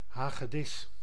Ääntäminen
IPA: /ˌɦaːɣəˈdɪs/